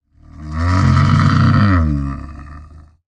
assets / minecraft / sounds / mob / camel / stand1.ogg